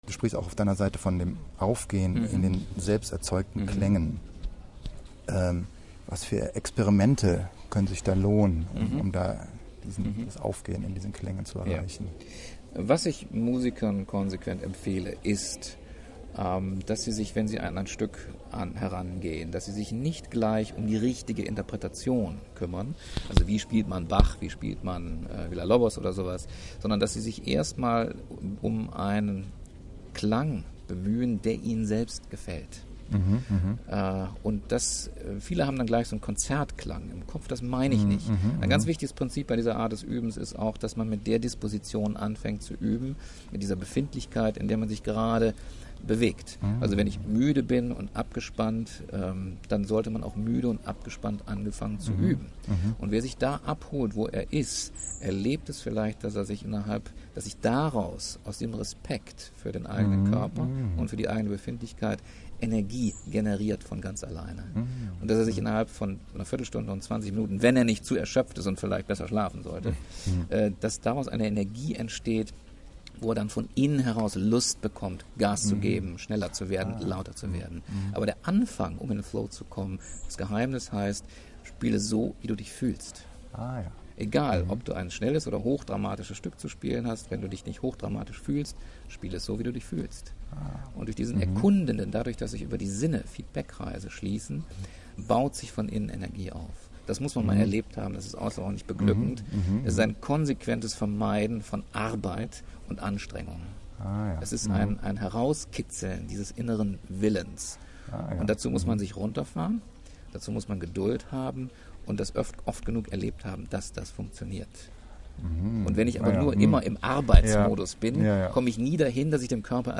Bericht vom Seminar & Audio Interview
Interview